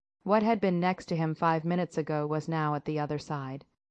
Text-to-Speech
Add clones